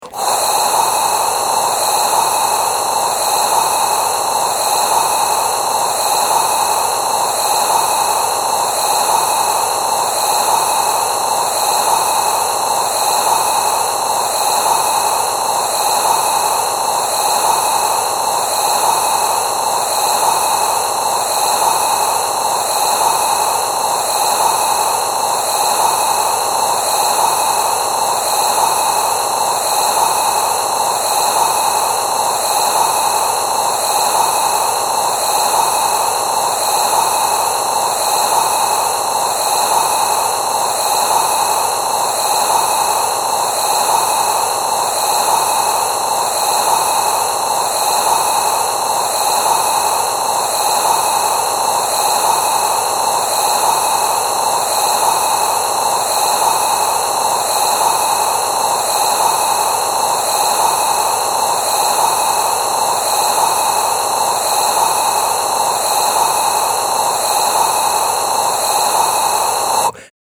dbf-brown-noise.mp3